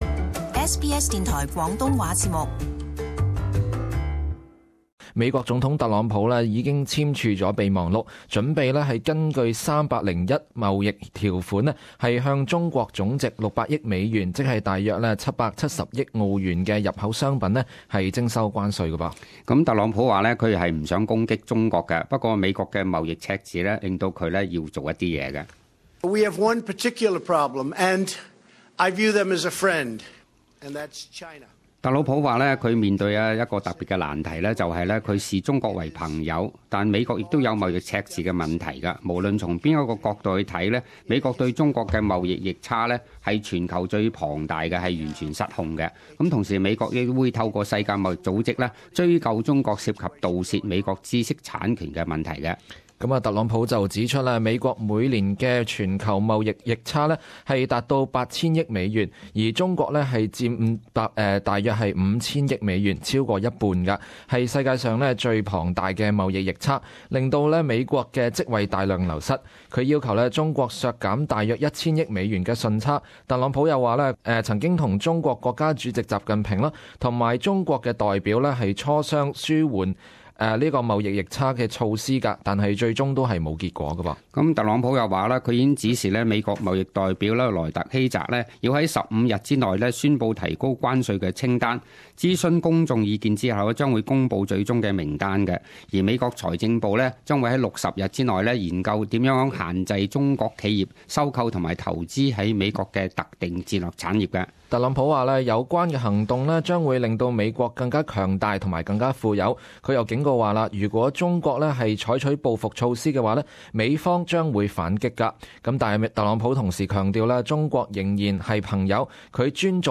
【时事报导】美国计划向总值600亿美元的中国商品征收关税